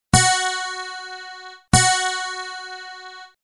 Pst... Klicka på tonerna så kan du stämma din gitarr efter ljudet!